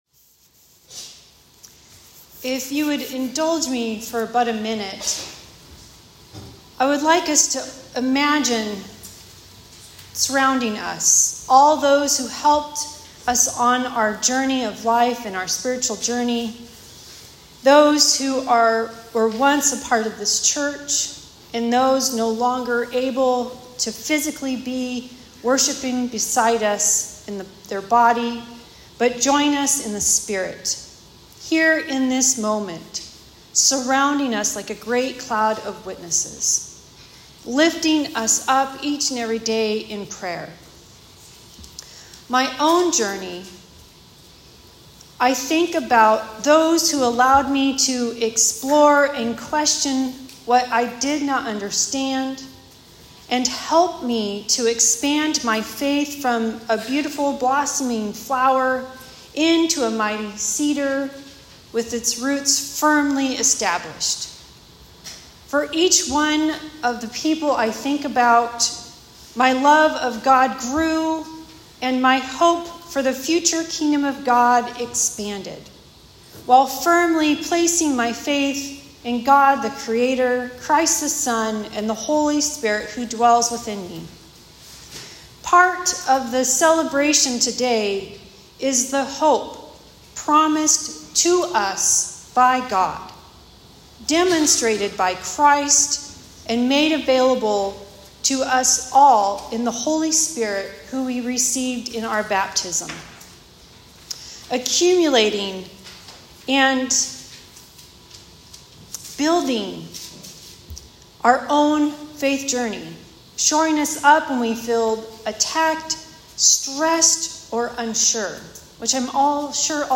In this Season of unrest and the multiple areas causing us stress, this weeks sermon points us towards the hope we have been promised in God.